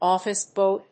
アクセントóffice bòy